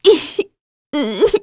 Aselica-Vox_Sad_kr.wav